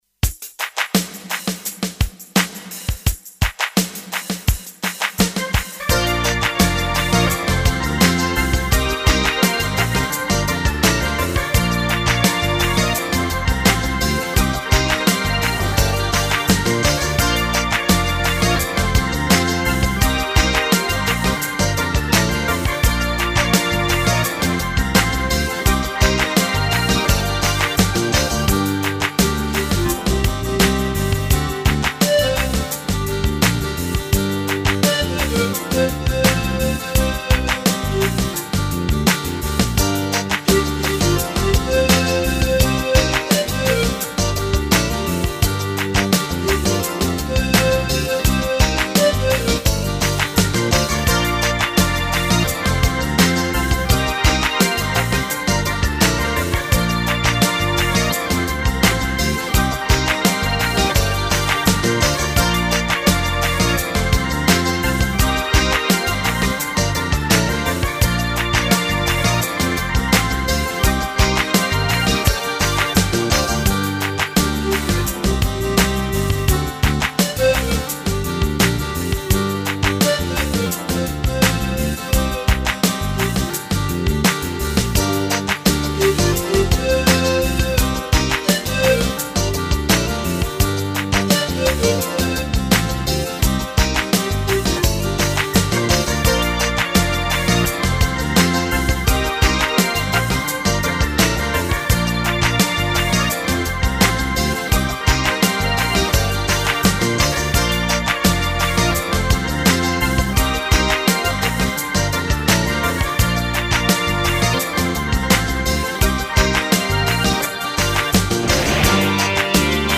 Разве что, караоке...